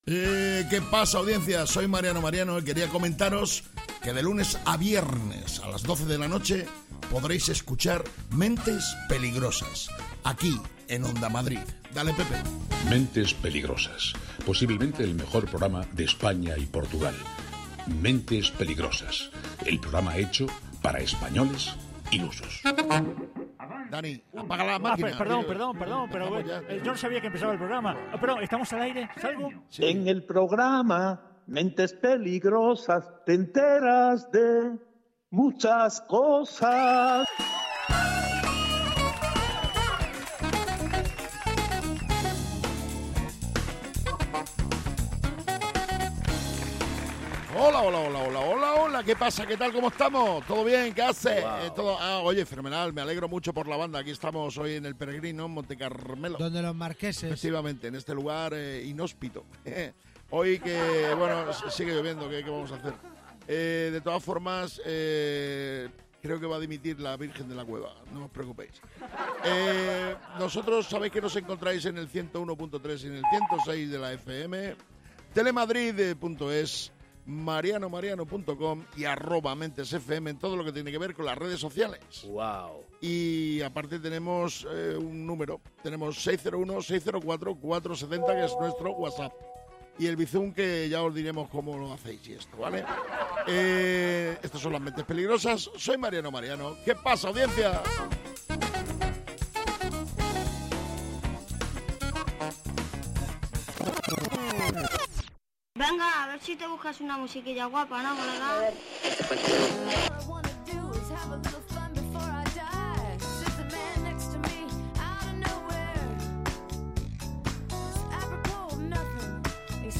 Mentes Peligrosas es humor, y quizás os preguntaréis, ¿y de qué tipo de humor es?